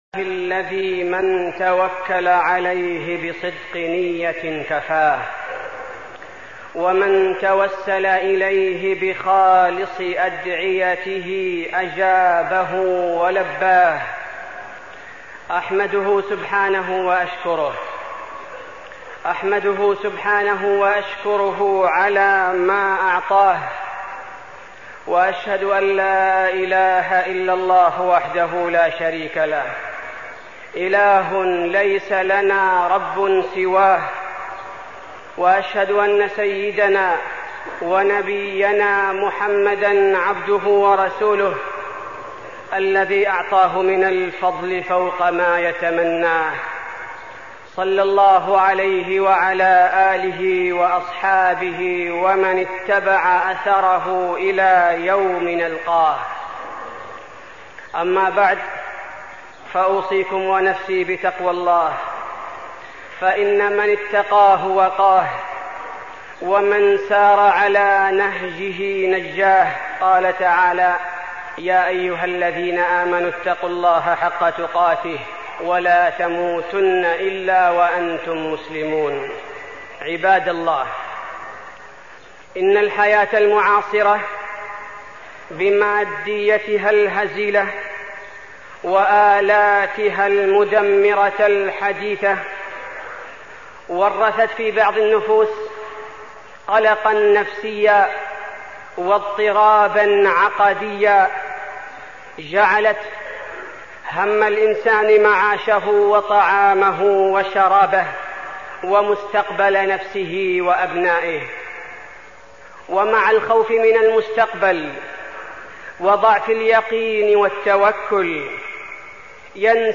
تاريخ النشر ١٩ شوال ١٤١٦ هـ المكان: المسجد النبوي الشيخ: فضيلة الشيخ عبدالباري الثبيتي فضيلة الشيخ عبدالباري الثبيتي التوكل على الله